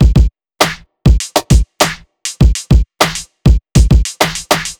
TI100BEAT2-L.wav